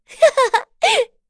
Dosarta-Vox-Laugh.wav